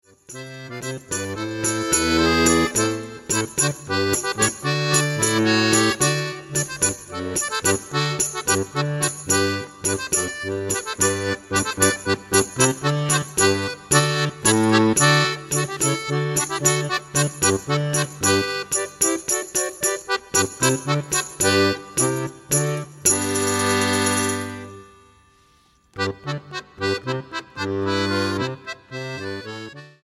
acordeón clásico